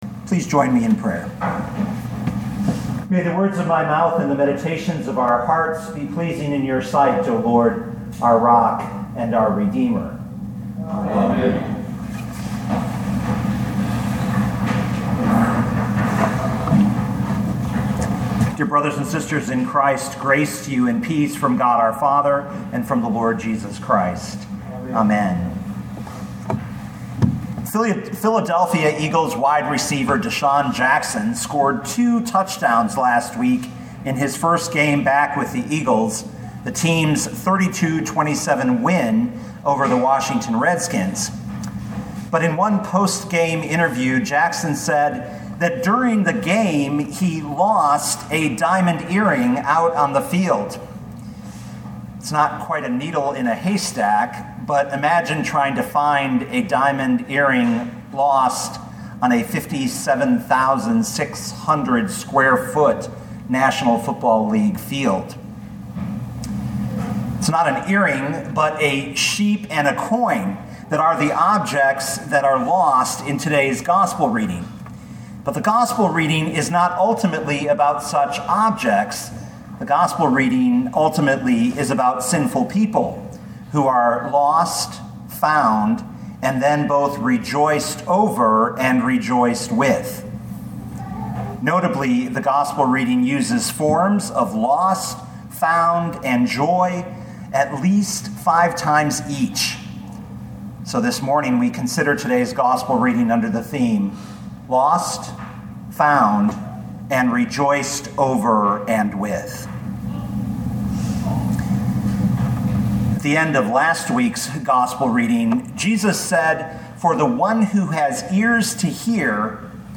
2019 Luke 15:1-10 Listen to the sermon with the player below, or, download the audio.